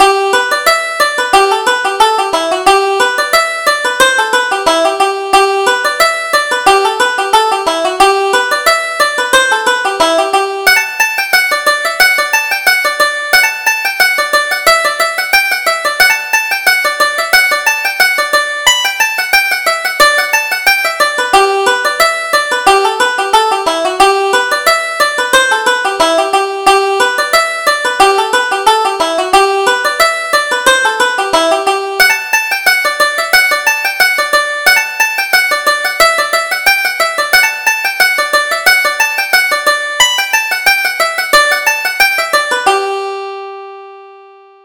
Reel: The Happy Days of Youth